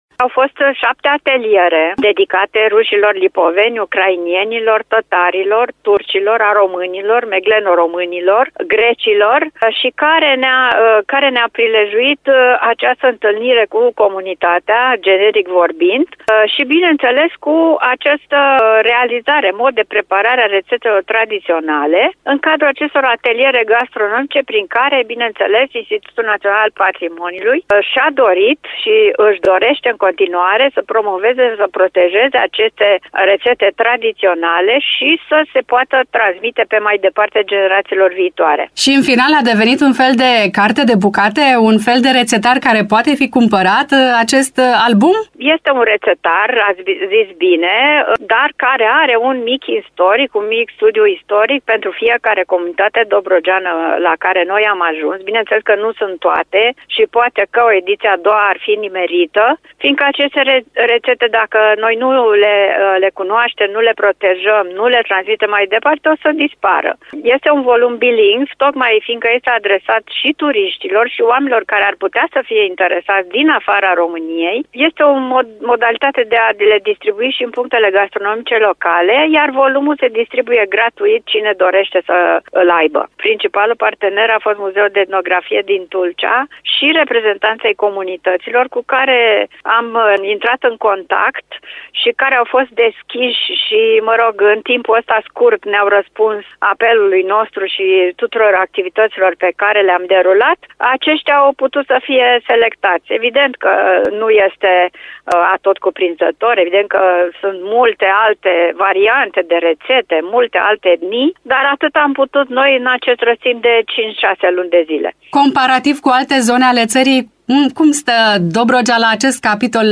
Dialoguri la zi: Discuție despre „Gustul Dobrogei” - interacțiunea dintre tradiție și modernitate, dintre local și global - Știri Constanța - Radio Constanța - Știri Tulcea